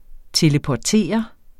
Udtale [ teləpɒˈteˀʌ ]